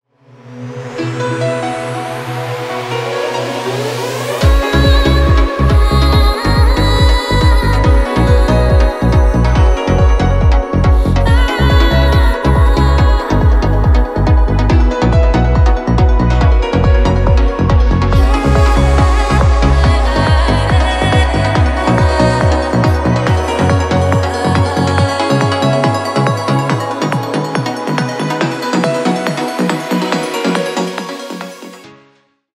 Электроника # без слов